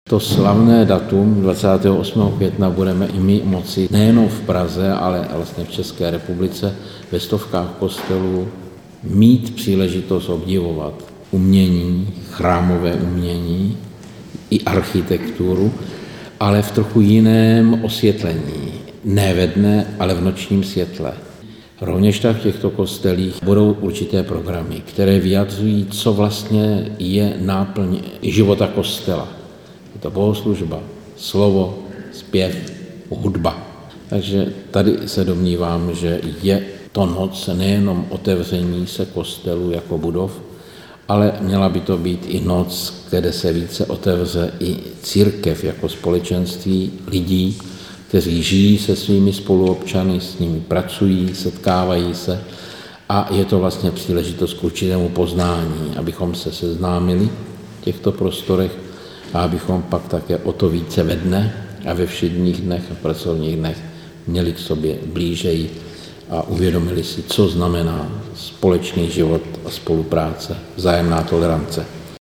Noc kostelů v pražské arcidiecézi není jen otevřením bohoslužebných prostor. "Jde o to představit také život kostela. Tím je bohoslužba, slovo, zpěv a hudba!" řekl novinářům arcibiskup pražský Mons. Dominik